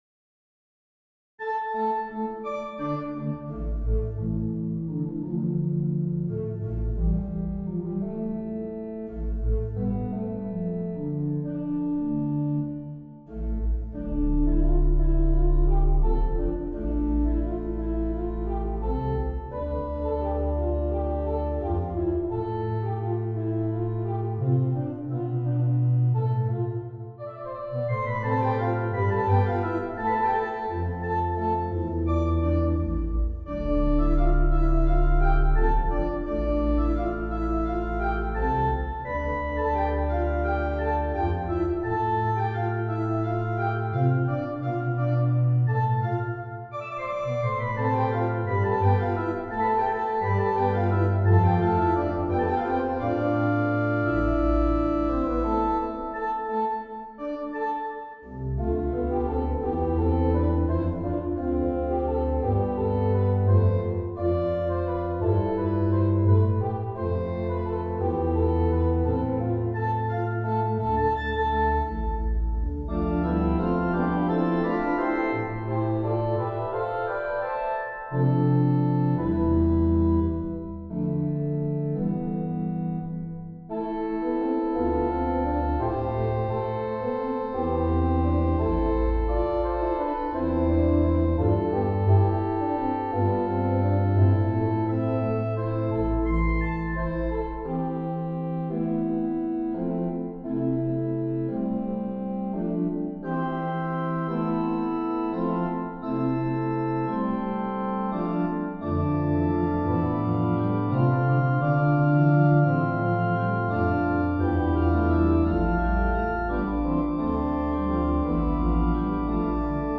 for organ
Here 8/8 is framed as 3+3+2, in a limpid, limping fughetta.
4 pages, circa 4' 00" an MP3 demo is here: